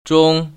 가. 집에 두고 볼 수 있는 시계([zhōng]